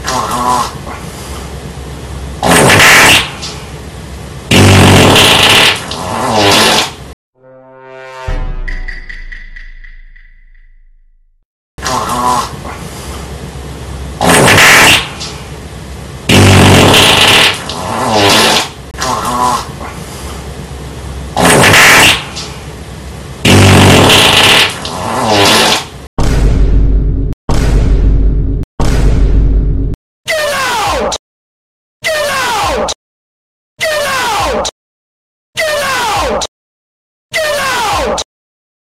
Farting Idiot: Instant Play Sound Effect Button